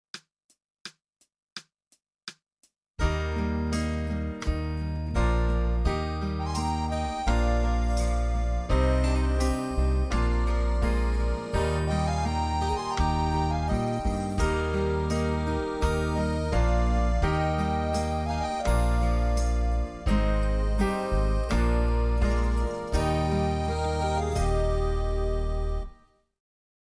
TROMBA SOLO • ACCOMPAGNAMENTO BASE MP3
Trombone